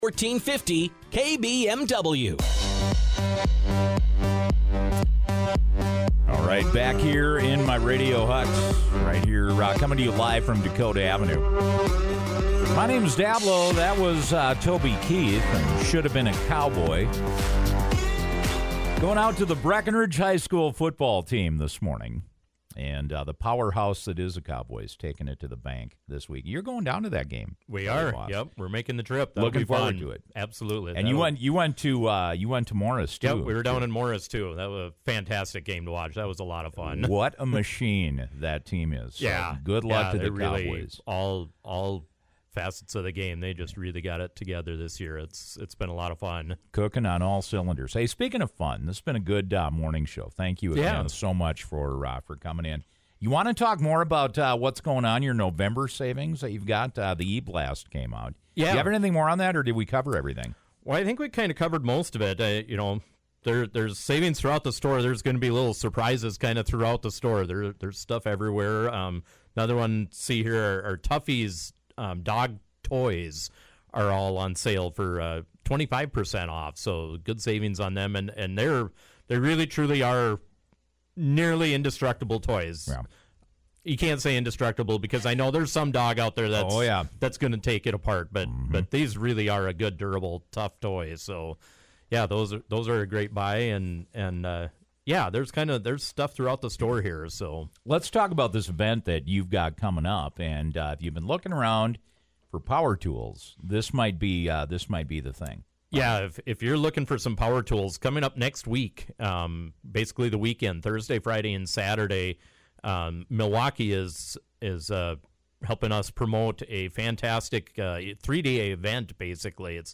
farm-city-sign-off.mp3